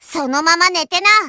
Worms speechbanks
Revenge.wav